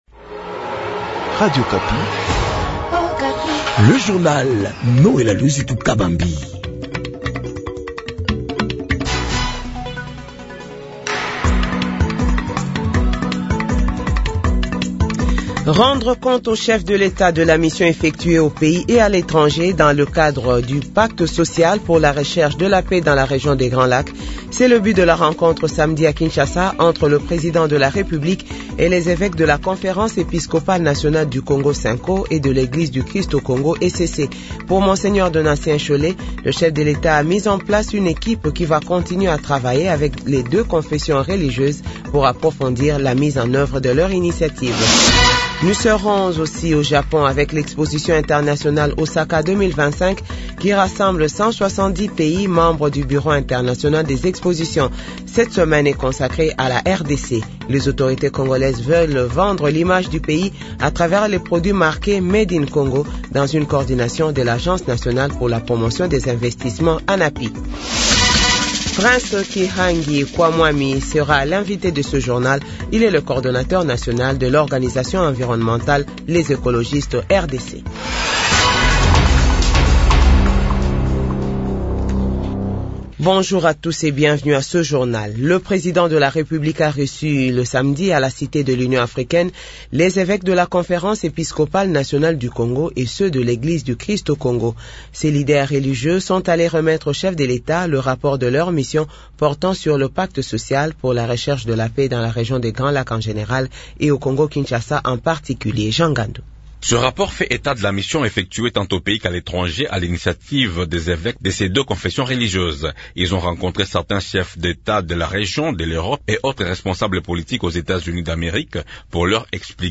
Journal 6h-7h